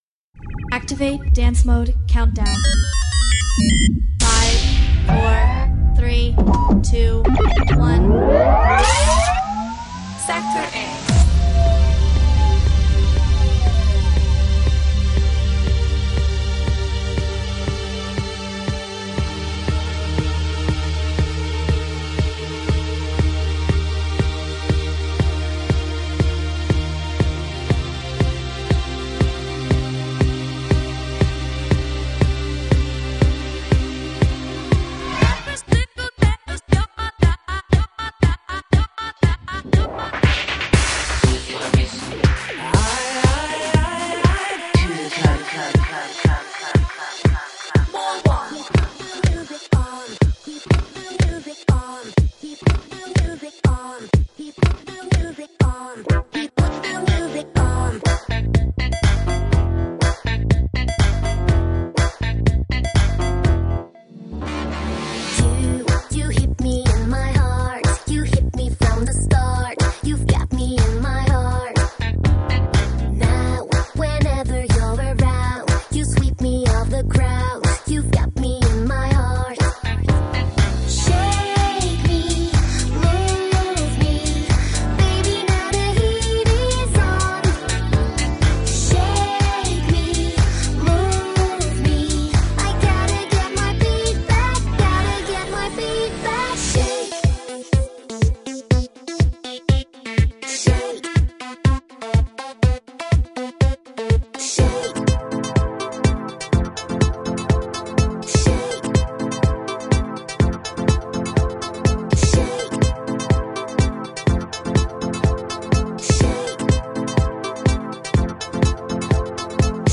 First mix down (2:06) 80kbps 32.0 Khz   (1.2 Mb) ( !